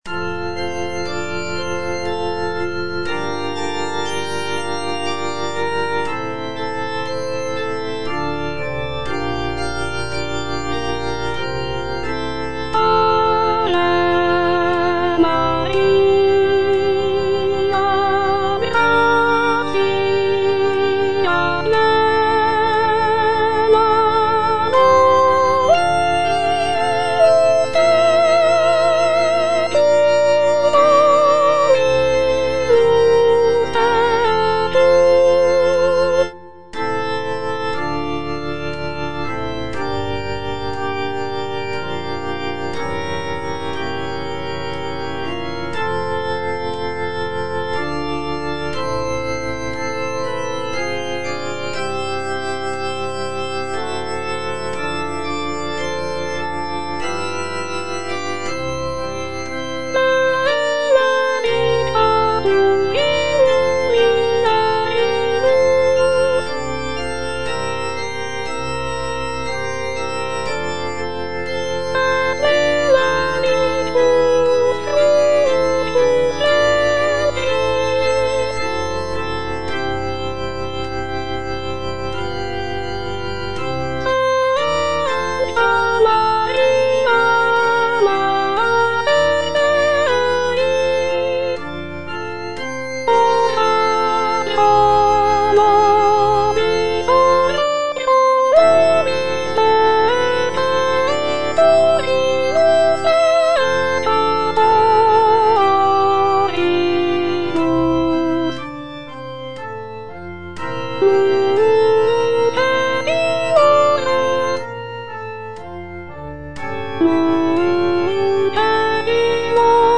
Soprano (Voice with metronome)